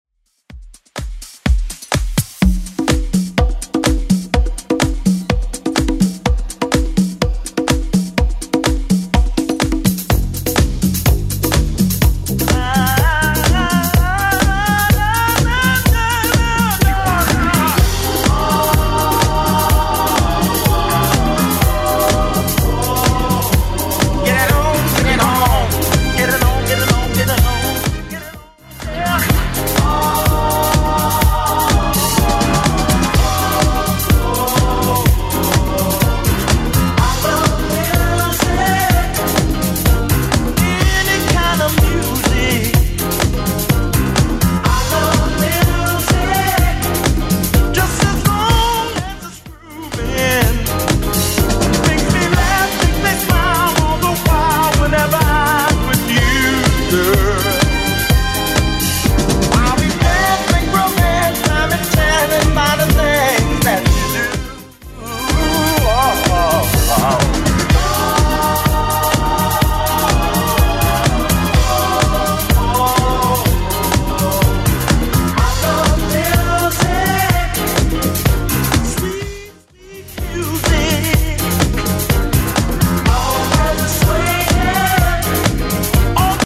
Genre: 80's
BPM: 97